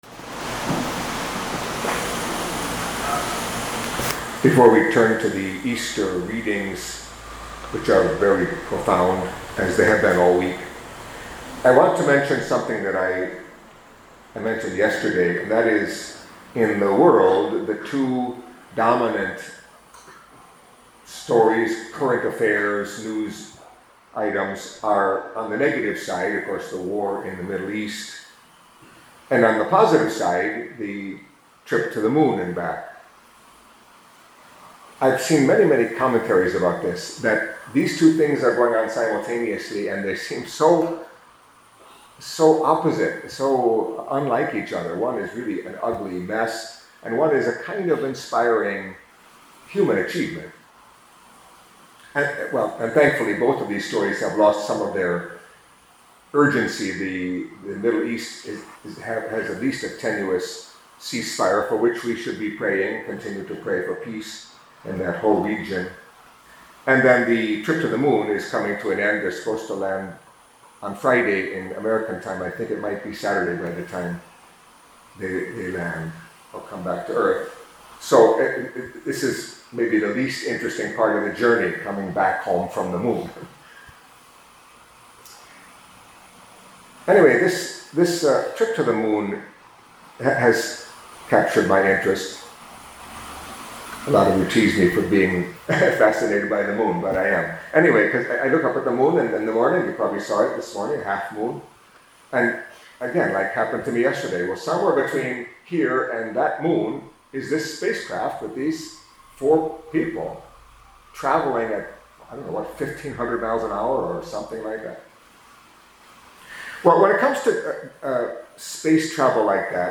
Catholic Mass homily for Friday in the Octave of Easter